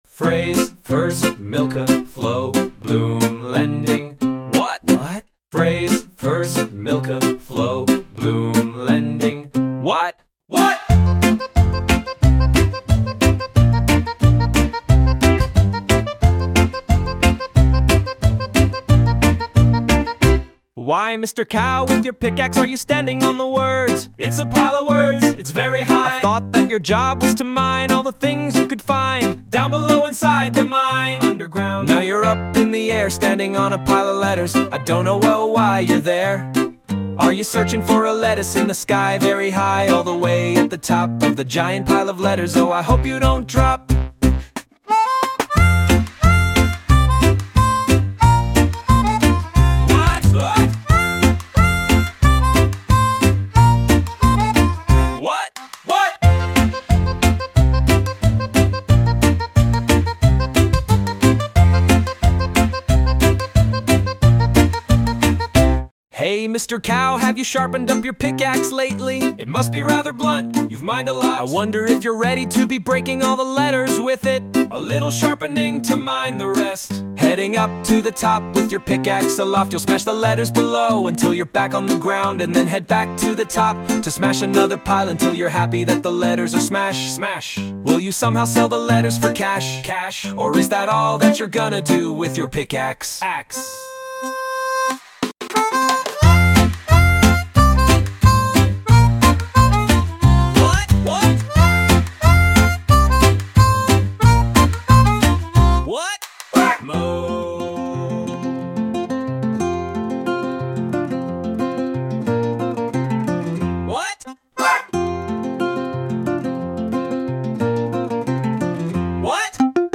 Sound Imported : Youthful Jumble
Sung by Suno